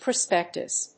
音節pro・spec・tus 発音記号・読み方
/prəspéktəs(米国英語), prʌˈspektʌs(英国英語)/